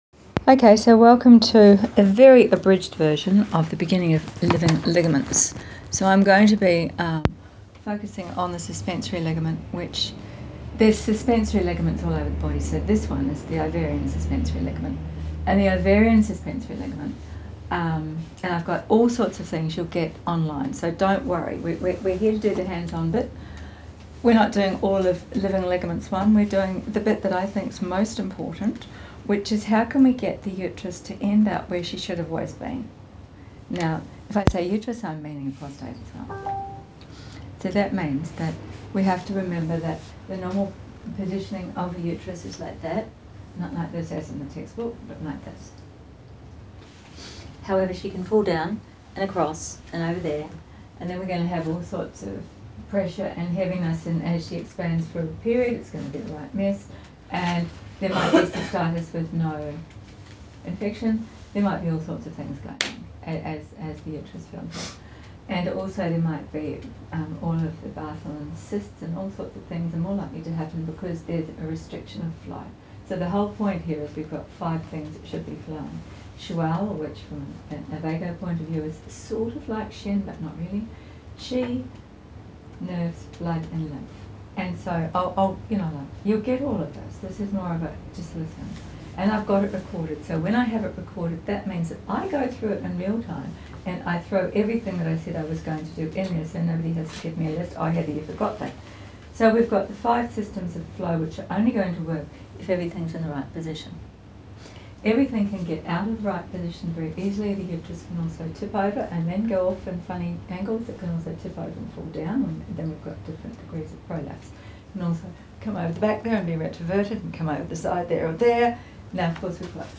Listen as I walk a group of keen acupuncturists through why we need womb aligned.